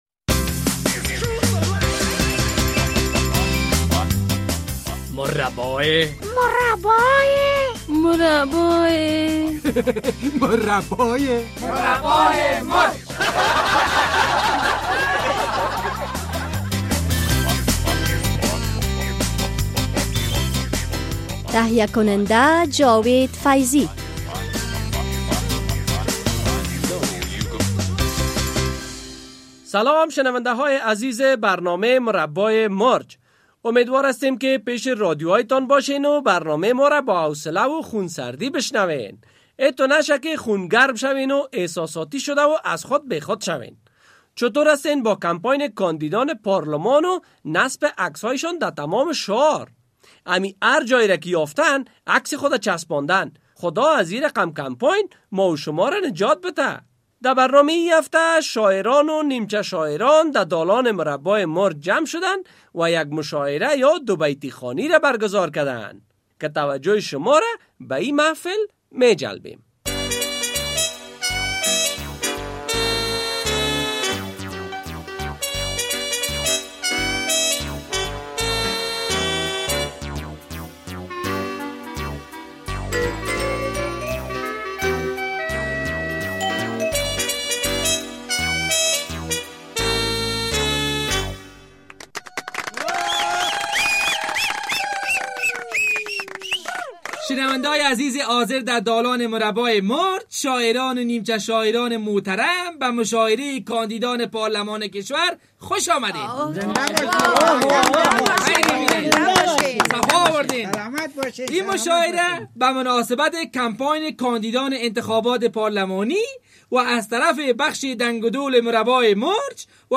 ده برنامه ای هفته شاعران و نیمچه شاعران ده دالان مربامرچ جمع شدن و یک مشاعره یا دوبیتی خوانی ره ...